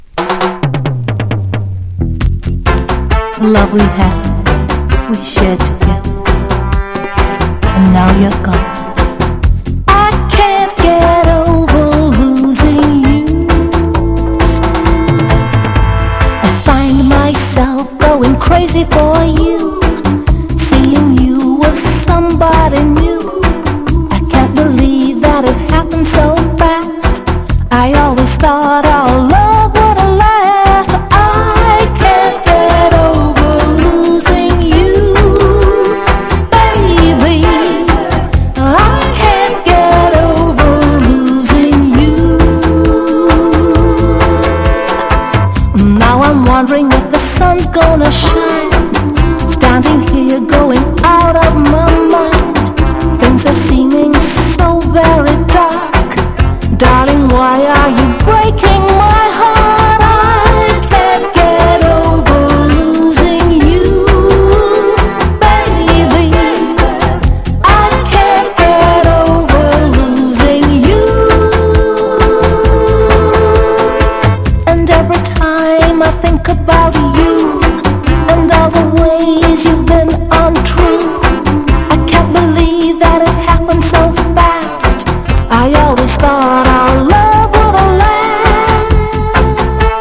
• REGGAE-SKA
# DUB / UK DUB / NEW ROOTS# LOVERS